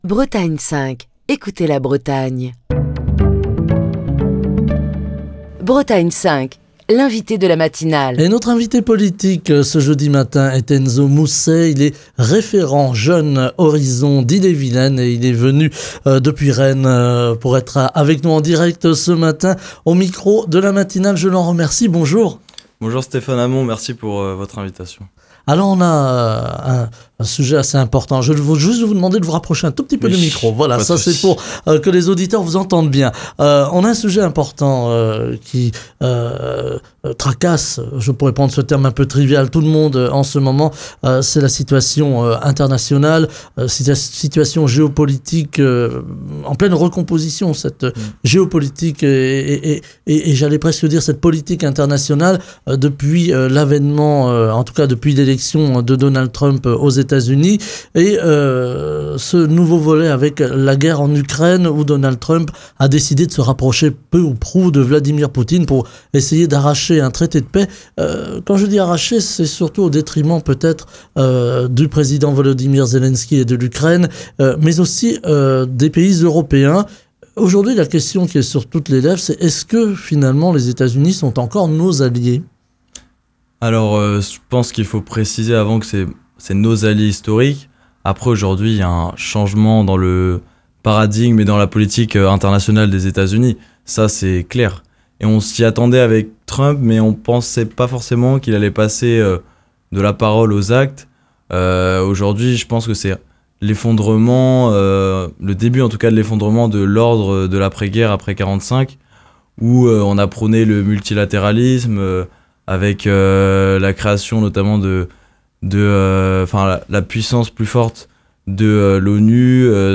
était l'invité politique de la matinale de Bretagne 5. L'occasion pour lui de réagir longuement à l'allocution d'Emmanuel Macron, qui s'exprimait hier soir à la télévision, au sujet de l'Ukraine et des répercussions de la politique américaine en Europe, particulièrement depuis le rapprochement entre Donald Trump et Vladimir Poutine.